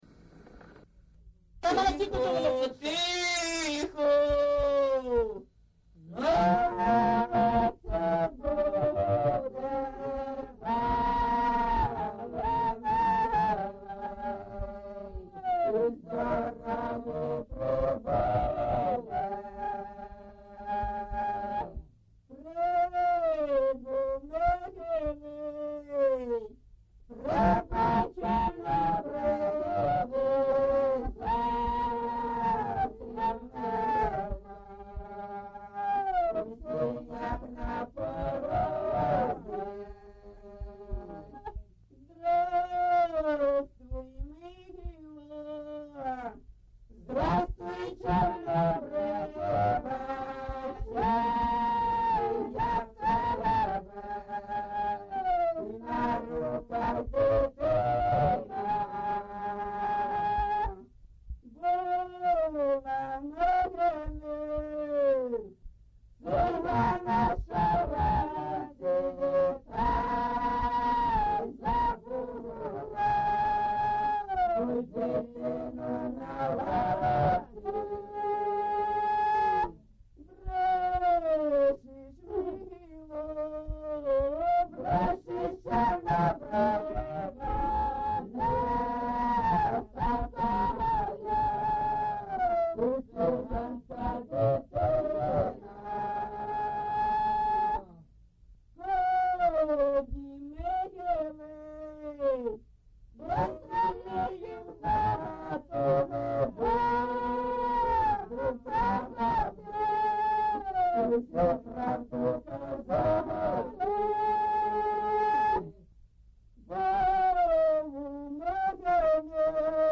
ЖанрПісні з особистого та родинного життя, Балади
Місце записус. Олексіївка, Великоновосілківський (Волноваський) район, Донецька обл., Україна, Слобожанщина